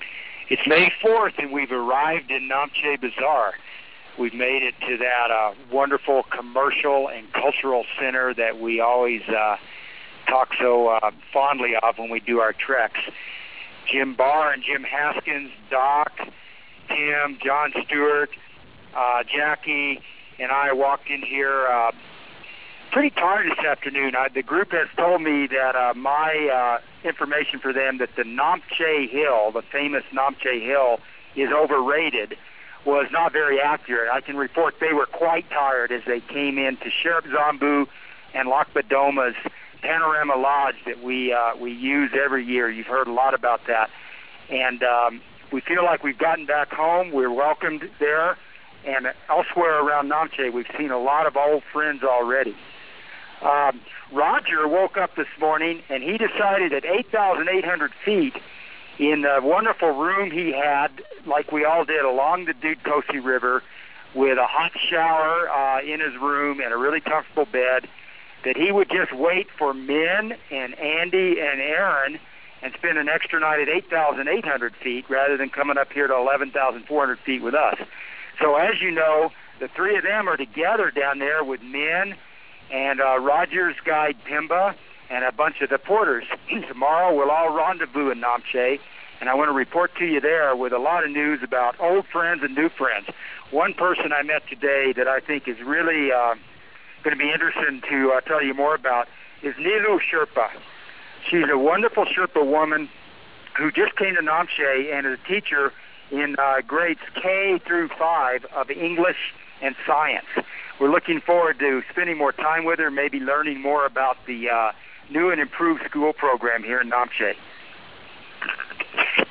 Berg Adventures International: Everest Base Camp Expedition Cybercast